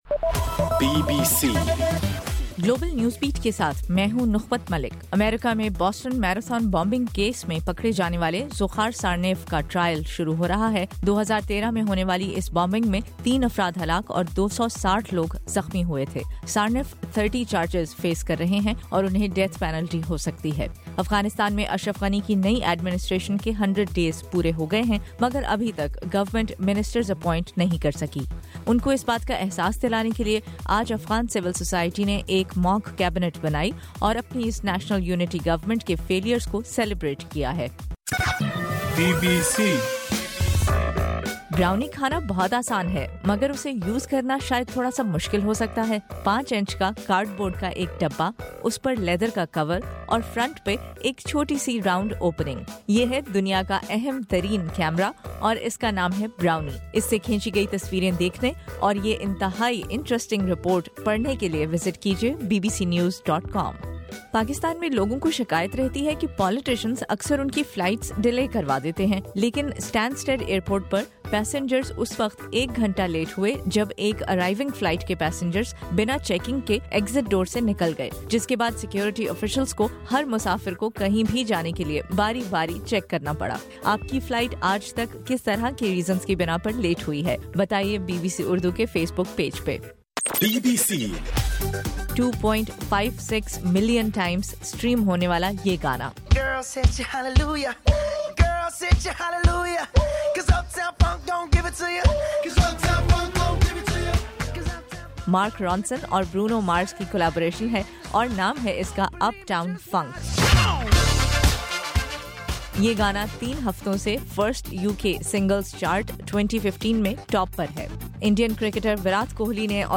جنوری 5: رات 10 بجے کا گلوبل نیوز بیٹ بُلیٹن